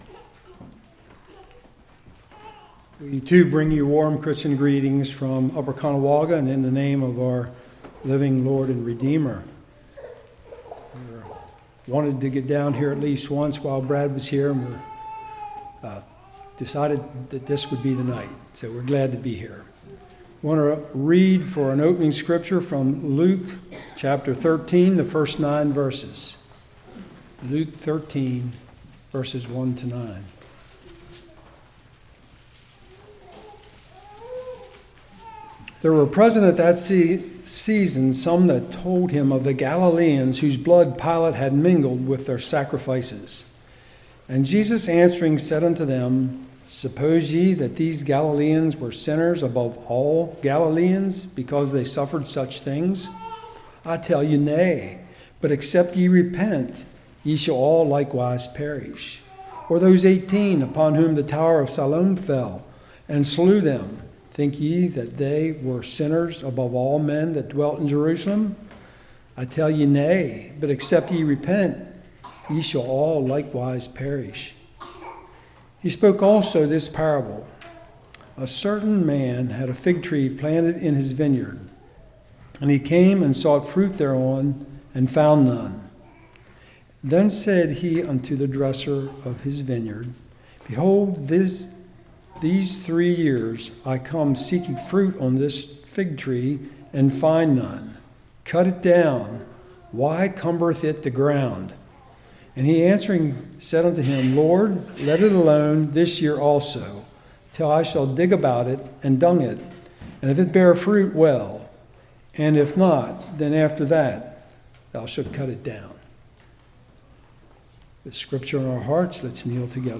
Luke 13:1-9 Service Type: Revival Is The Christian Life A Breeze?